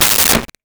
Toilet Seat Down 1
toilet-seat-down-1.wav